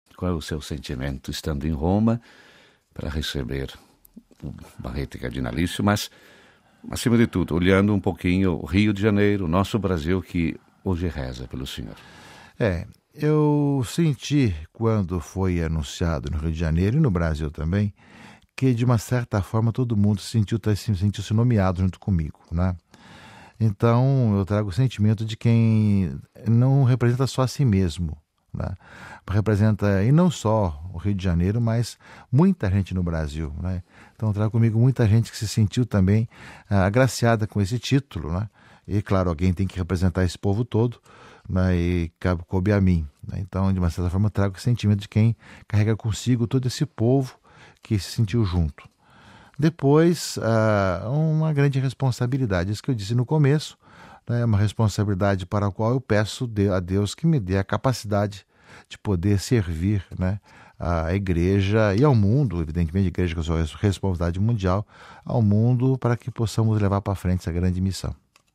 Clique acima para ouvir a voz do Cardeal.